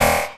MZ Perc [Drill].wav